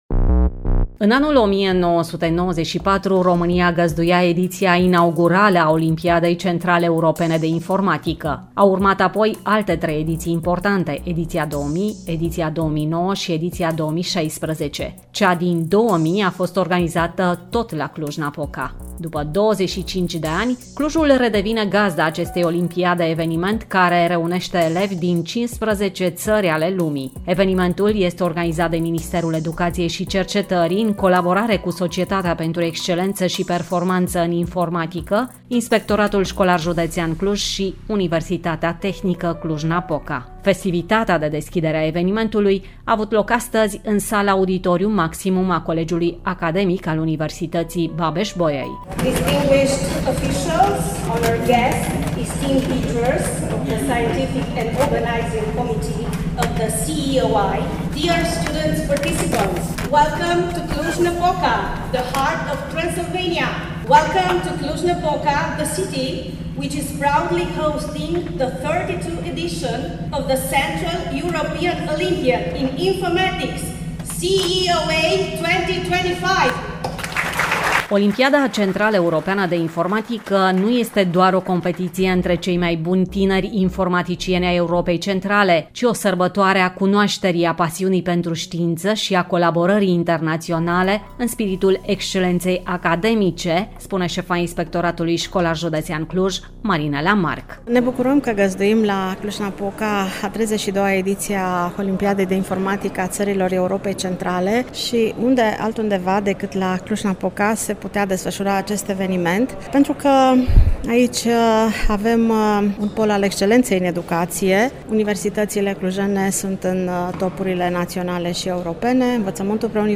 Festivitatea de deschidere a evenimentului a avut loc marți, 8 iulie, în Sala Auditorium Maximum a Colegiului Academic al Universității „Babeș-Bolyai”.